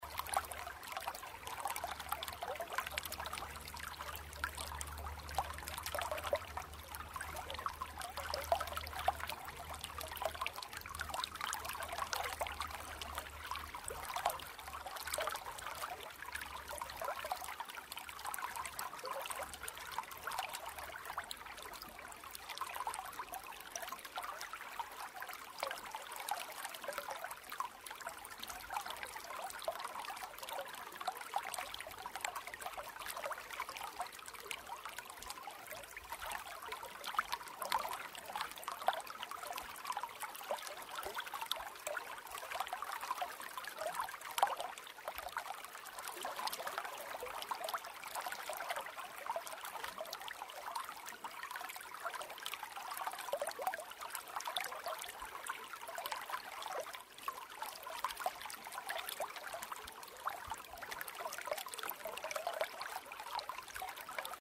Звуки журчащего ручья
Погрузитесь в атмосферу природы с подборкой звуков журчащего ручья.